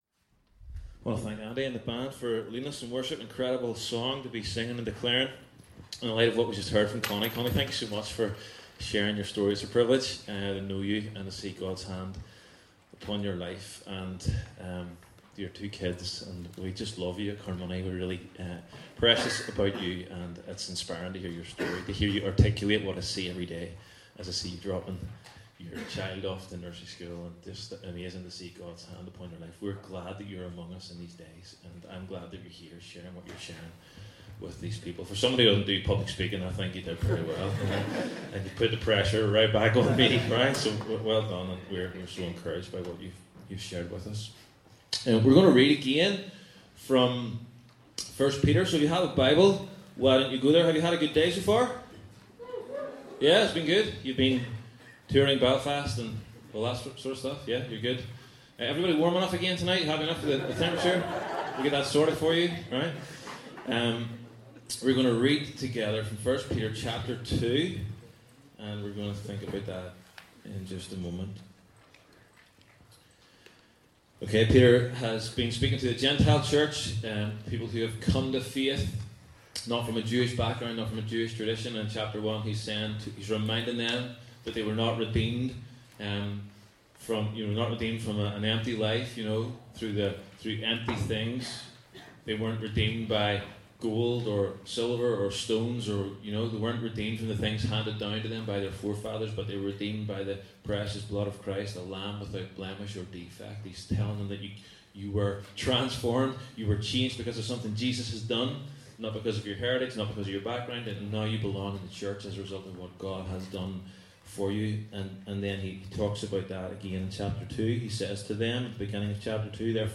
Transform seeks to connect a generation of young adults from across the Presbyterian Church in Ireland, equip them to be disciples of Jesus and inspire them to live this out in the every day. This recording is taken from the Transform Gathering which took place in the Cathedral Quarter, Belfast from 1st-2nd April 2016.